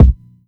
• Kick Sound C Key 649.wav
Royality free bass drum single shot tuned to the C note. Loudest frequency: 134Hz
kick-sound-c-key-649-uh0.wav